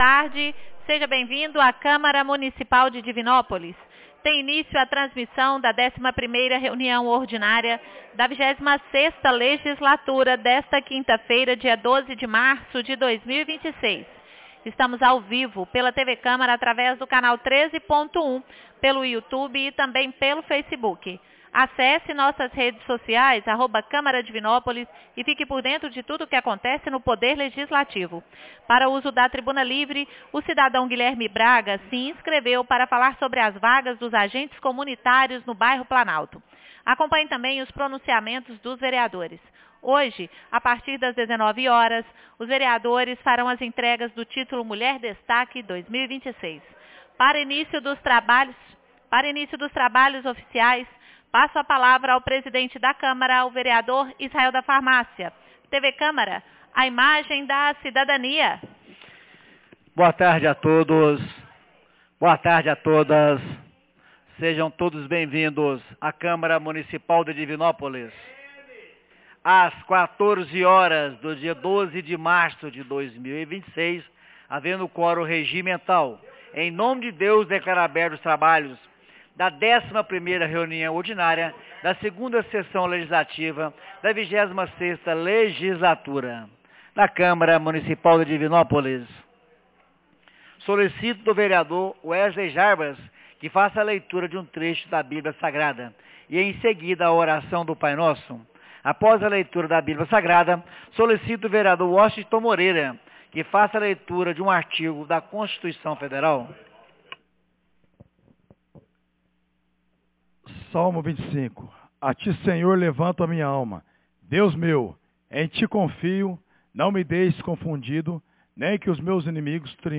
11ª Reunião Ordinaria 12 de março de 2026